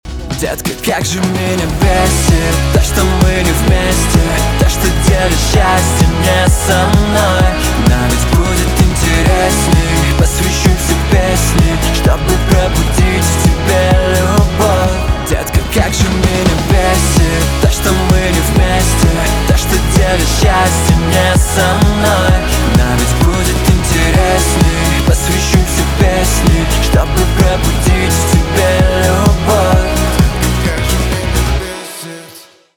поп
грустные
романтические
гитара